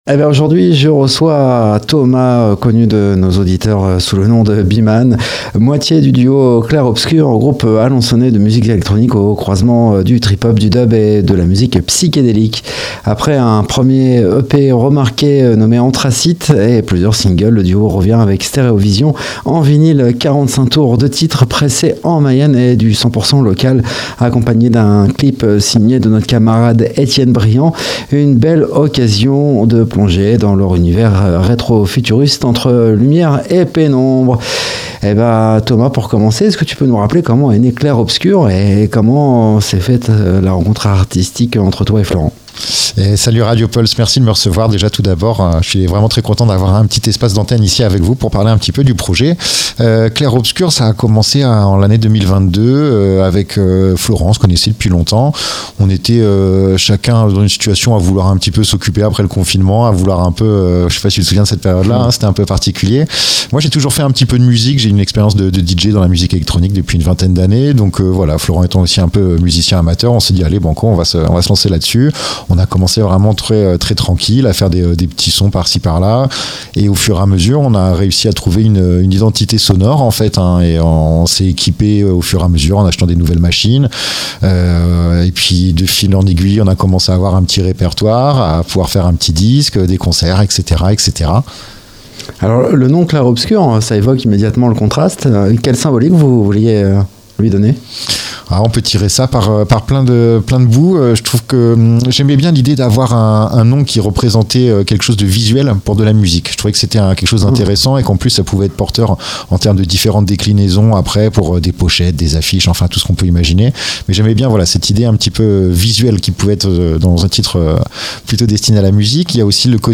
Dans cette interview